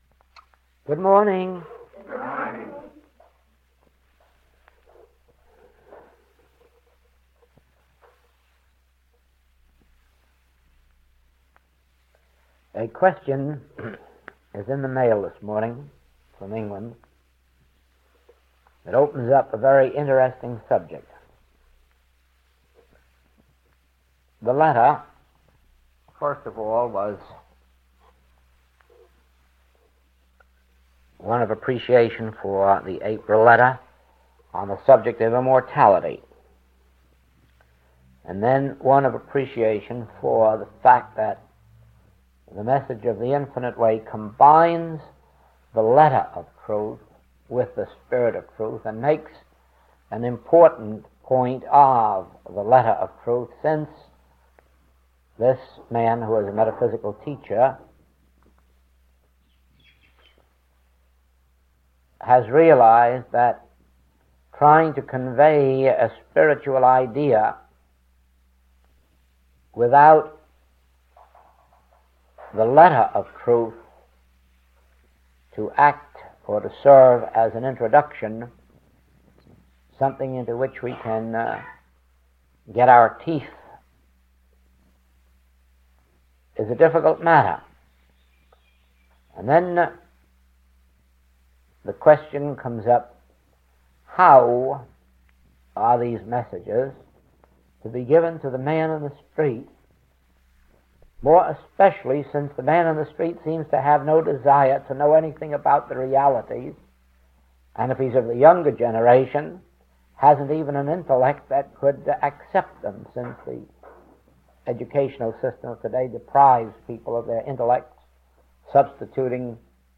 Recording 113B is from the 1955 Kailua Study Group.